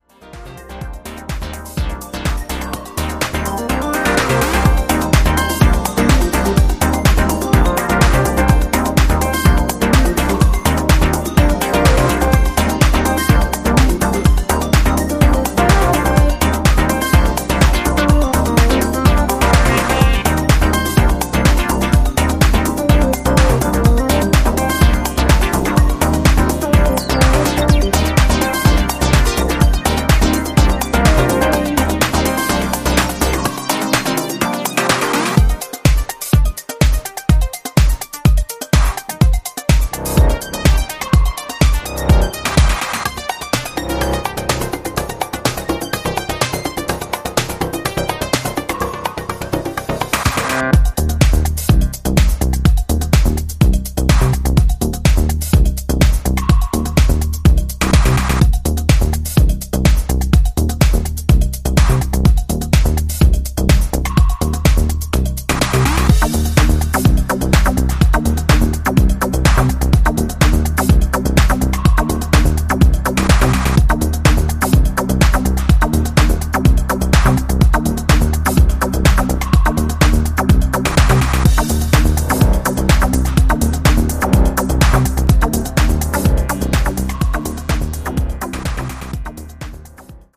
ここでは、豊潤なシンセレイヤーが躍動するメロディックかつエネルギッシュなハウス・トラックスを展開。